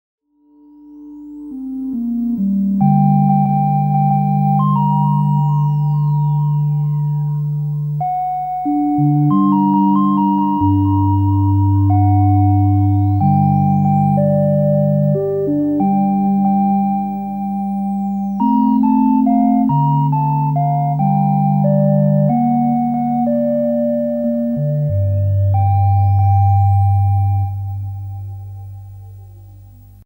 G minor